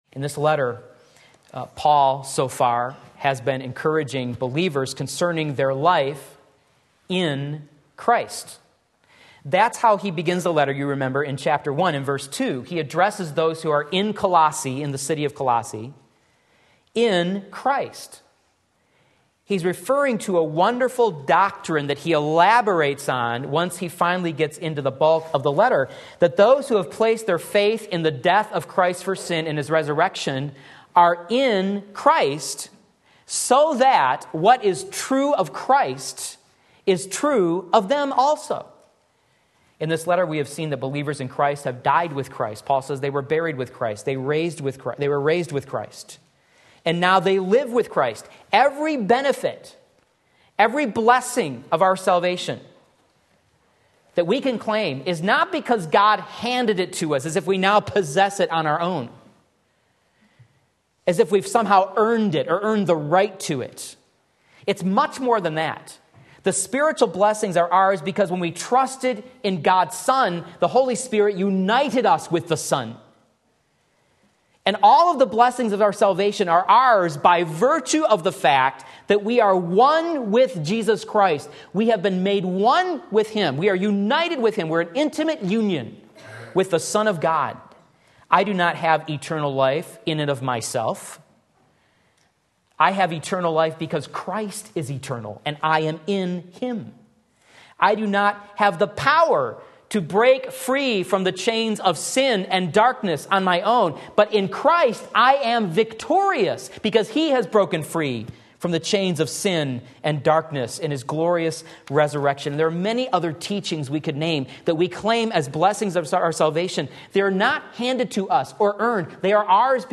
Sermon Link
Sunday Morning Service